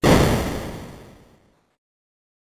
explo1.wav